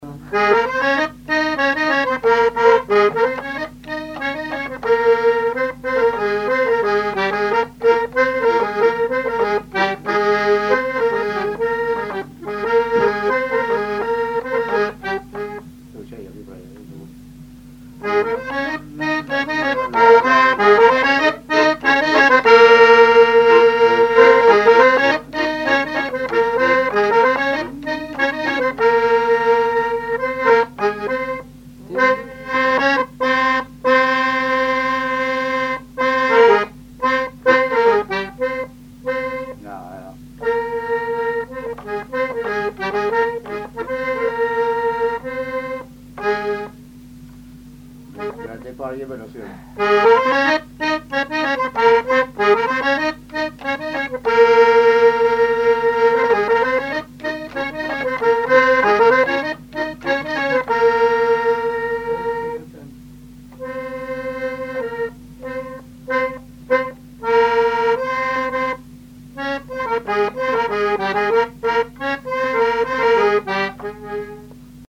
Saint-Hilaire-de-Riez
Chants brefs - A danser
danse : sicilienne
Pièce musicale inédite